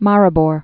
(märĭ-bôr)